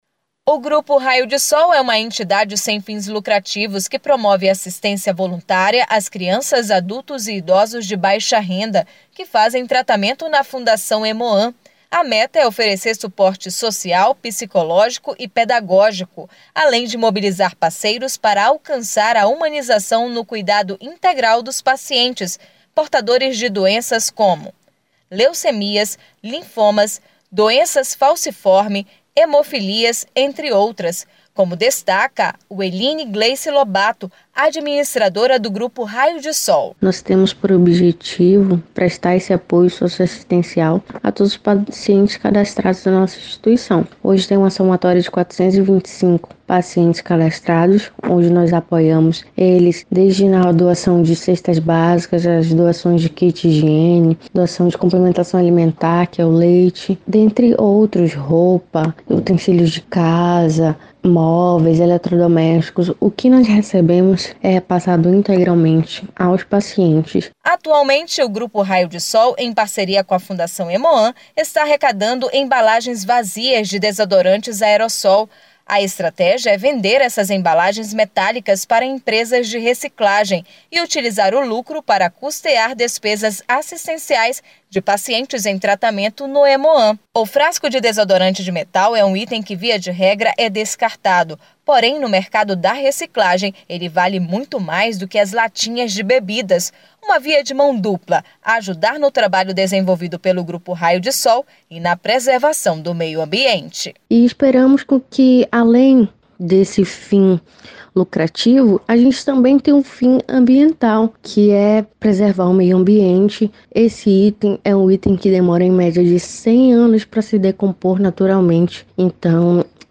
Ouça esta matéria em áudio